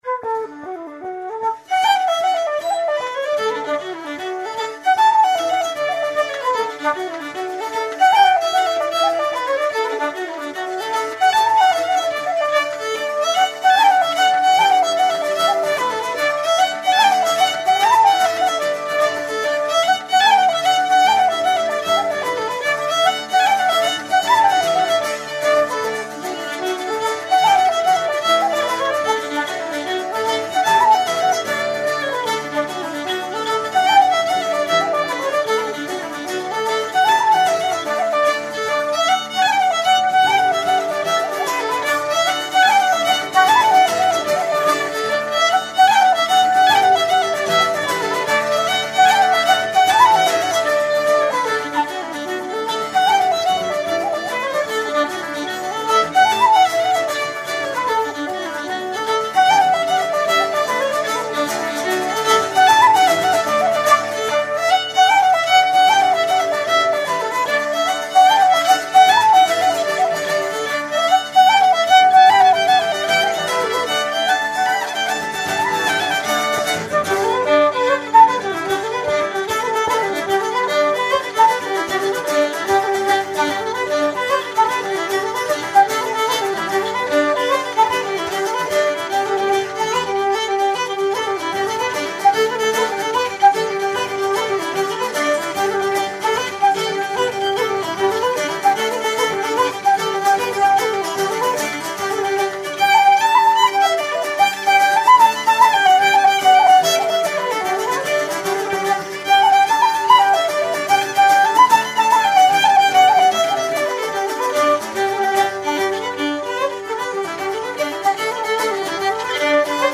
bouzouki
flute
fiddle
guitar